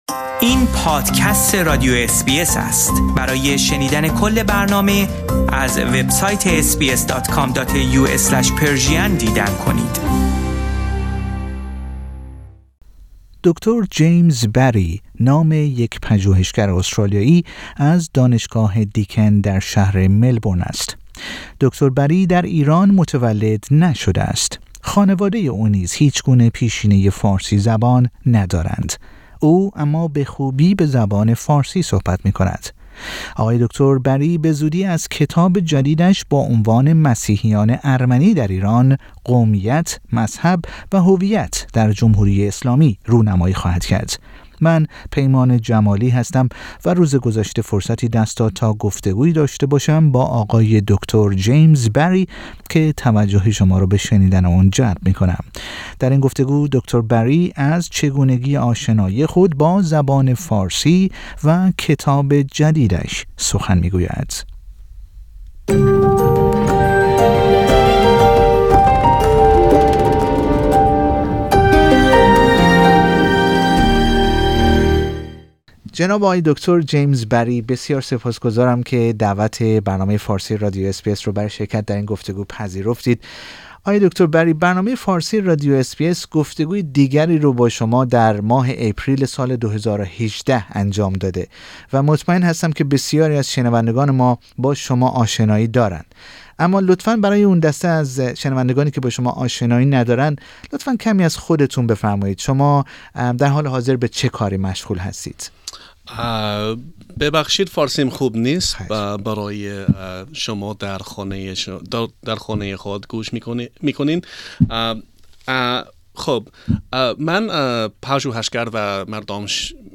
در گفتگو با برنامه فارسی رادیو اس بی اس درباره خود، چگونگی یاد گرفتن زبان فارسی و کتاب جدیدش سخن می گوید.